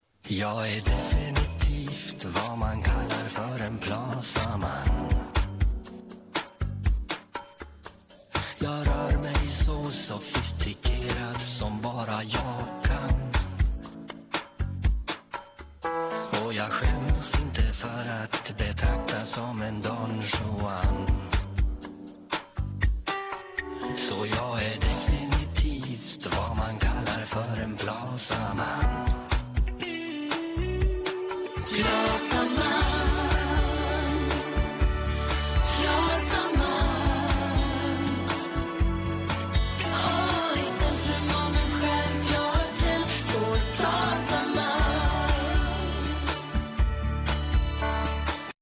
Musiker